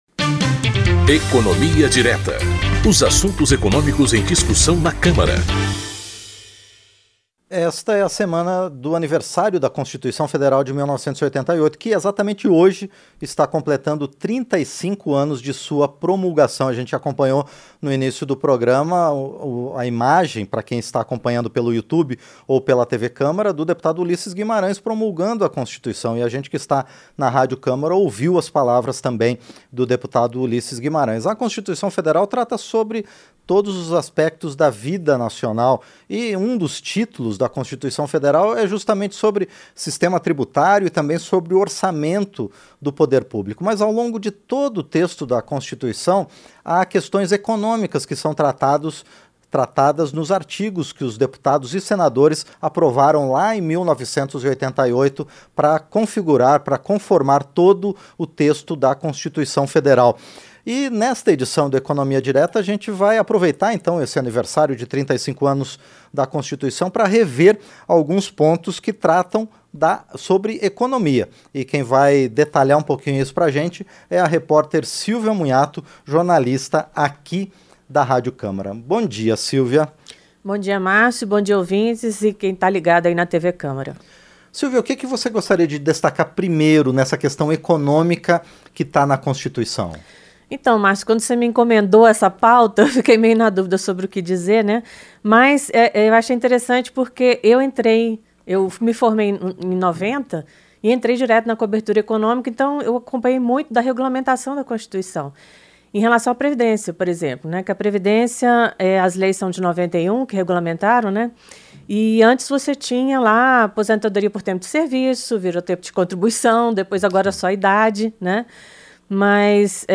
Ela destaca que o primeiro artigo já coloca a livre iniciativa entre os fundamentos do Estado brasileiro, junto com o direito à propriedade, desde que cumpra sua função social. A jornalista também comenta sobre a defesa do consumidor, os direitos dos trabalhadores, o sistema tributário e o gerenciamento das contas públicas.